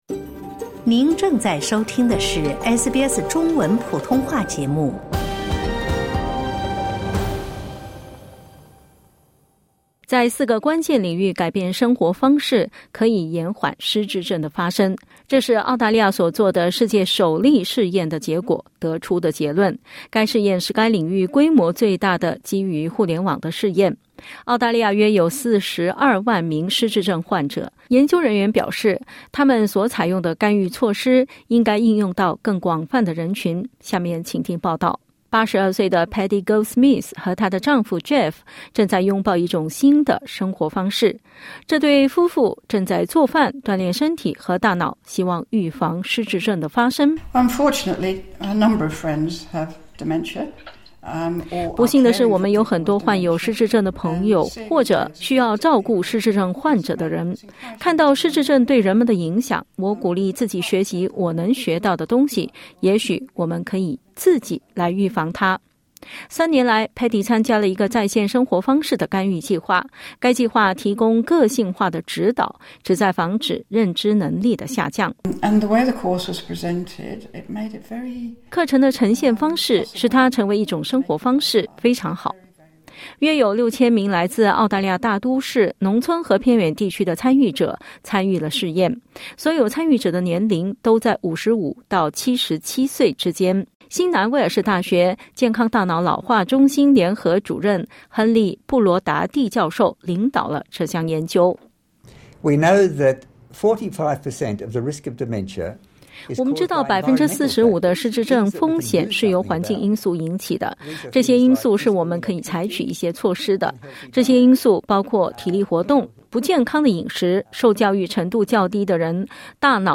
澳大利亚约有42万失智症患者，如何通过改变生活方式来延缓失智症？（点击音频收听详细报道）